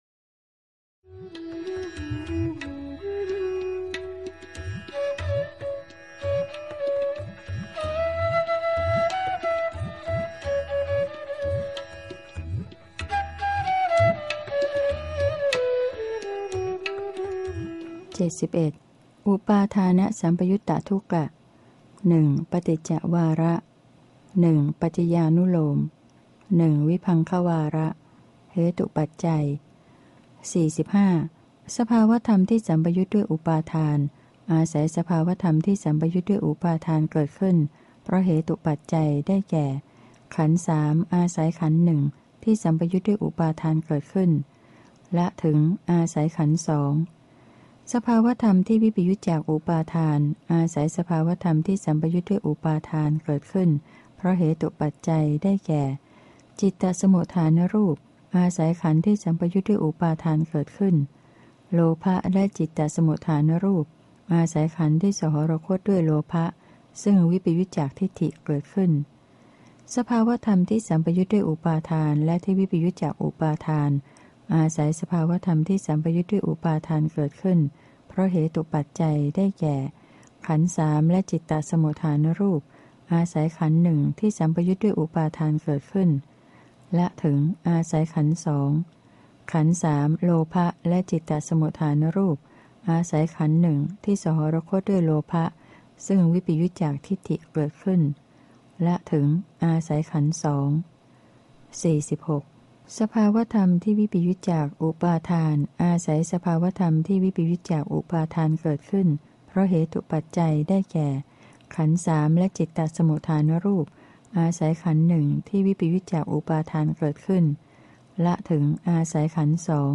พระไตรปิฎก ภาคเสียงอ่าน ฉบับมหาจุฬาลงกรณราชวิทยาลัย - เล่มที่ ๔๓ พระอภิธรรมปิฏก